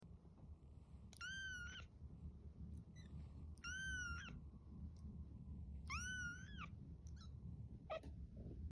Foster kittens meowing sounds like
Foster kittens meowing sounds like crying but he’s just a talkative guy